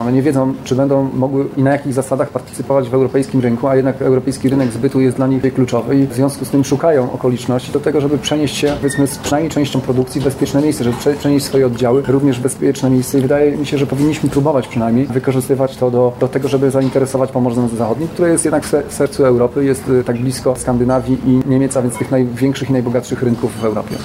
Dodatkowo, wiele firm hinduskich zlokalizowanych w Wielkiej Brytanii obawia się Brexitu. – To powoduje, że będą szukać bezpiecznych lokalizacji – przekonywał Geblewicz.